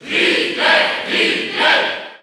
Category: Crowd cheers (SSBU) You cannot overwrite this file.
Ridley_Cheer_French_SSBU.ogg